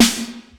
kits/RZA/Snares/WTC_SNR (53).wav at 32ed3054e8f0d31248a29e788f53465e3ccbe498